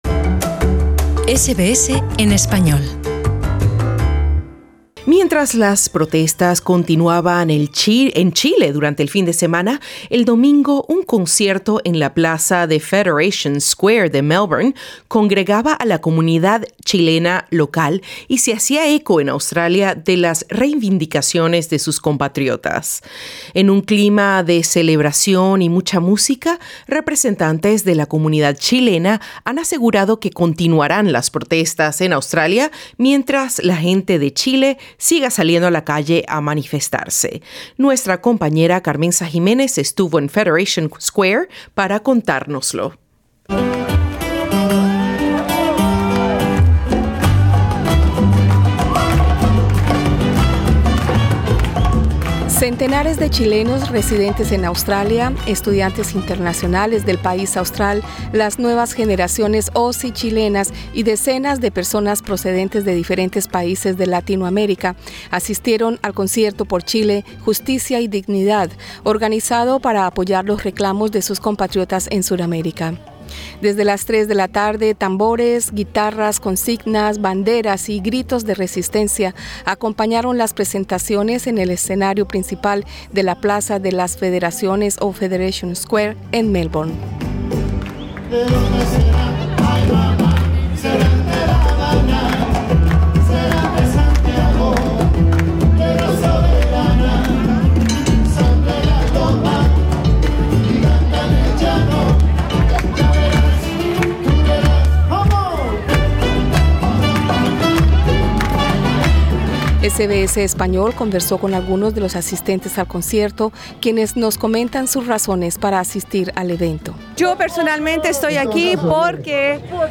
En un clima de celebración y mucha música, representantes de la comunidad chilena han asegurado que continuarán las protestas en Australia mientras la gente de Chile siga saliendo a la calle a manifestarse. En este concierto de solidaridad estuvo el cantautor Nano Stern, quien culminó una gira por Australia.